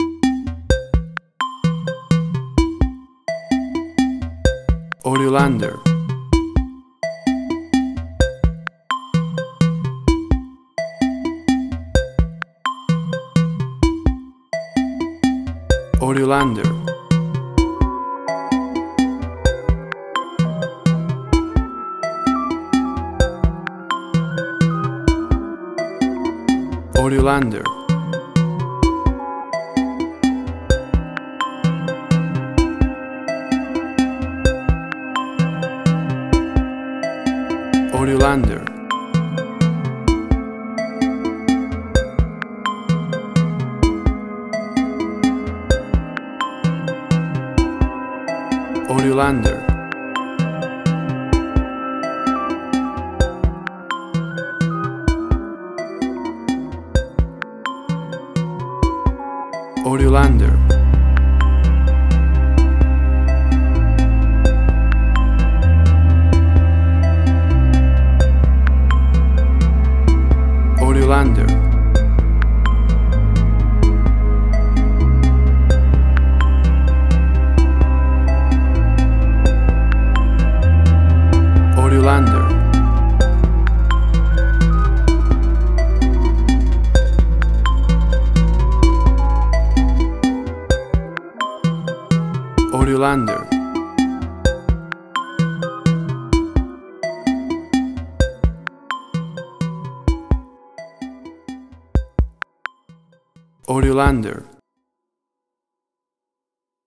Modern Science Fiction Film, Similar Tron, Legacy Oblivion
Tempo (BPM): 128